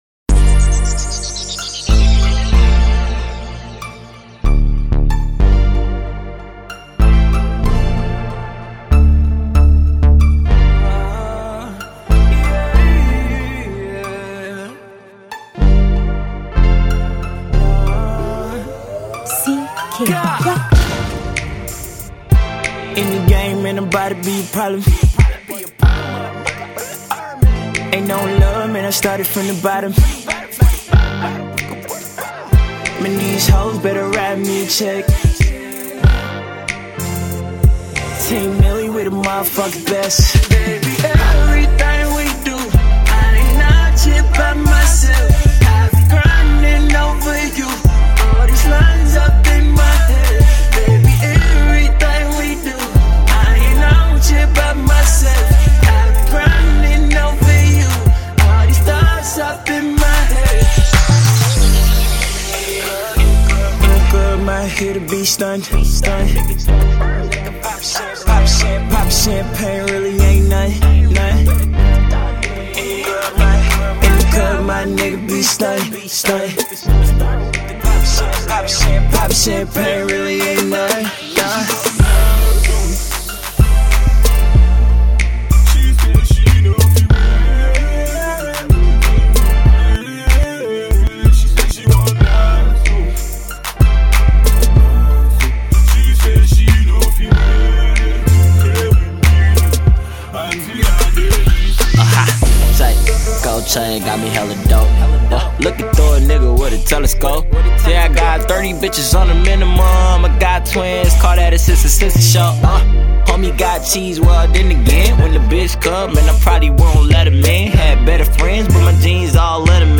Its a Feel good Rap song